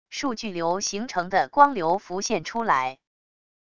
数据流形成的光流浮现出来wav音频